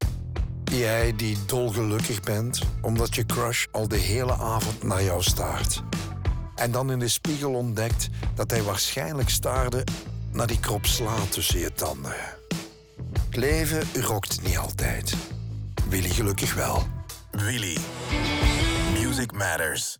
De audio in de geluidsstudio’s van DPG Media zelf
Radiospot Radio WIlly Krop.wav